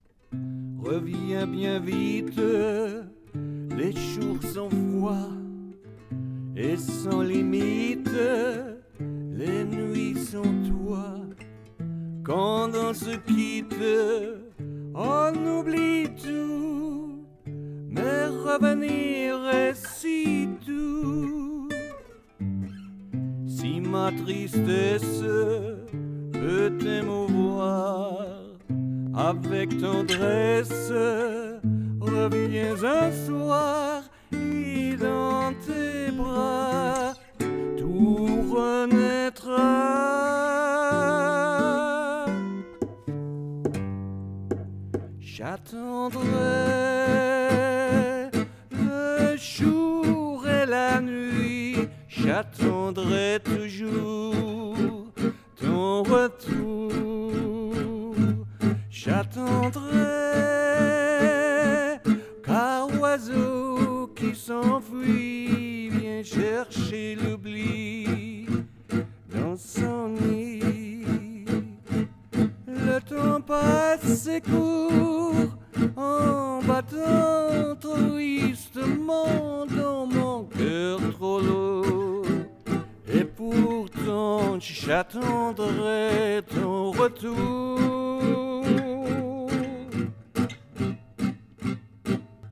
Here is a vocal version, an octave higher than the old one.